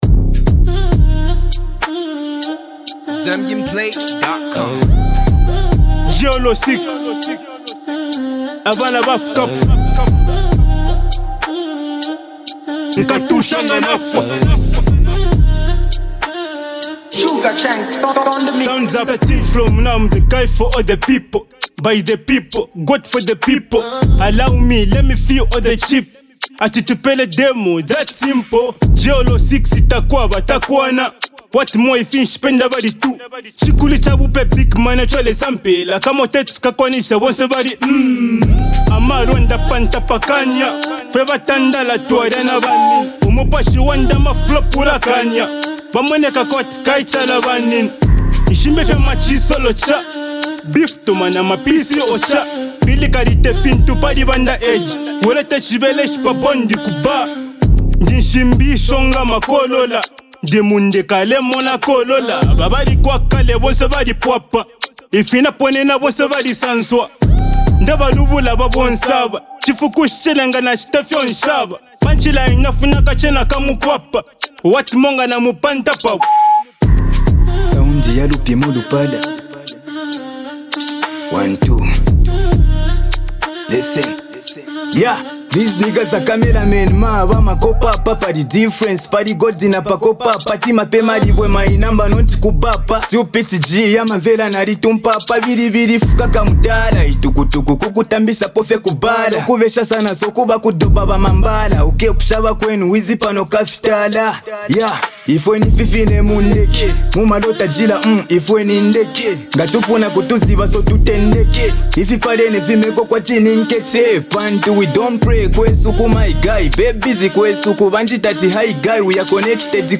Emerging Zambia rappers
deliver a powerful two-man freestyle